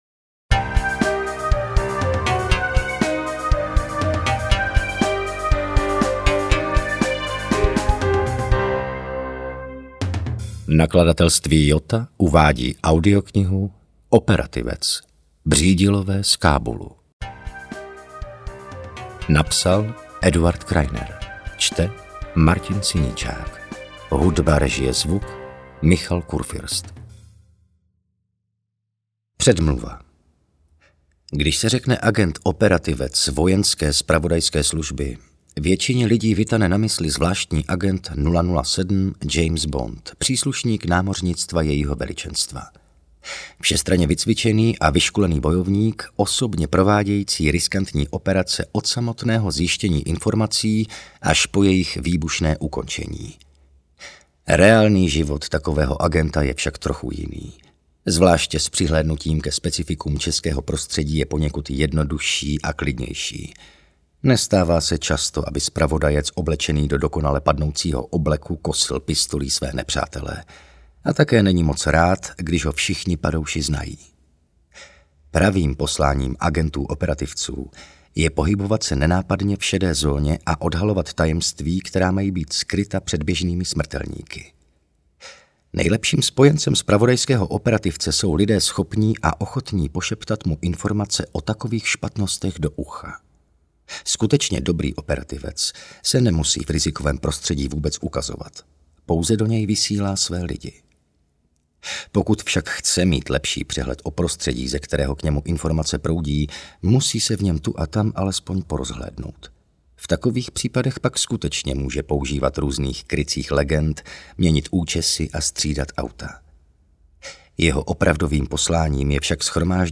Operativec II: Břídilové z Kábulu audiokniha
Ukázka z knihy
operativec-ii-bridilove-z-kabulu-audiokniha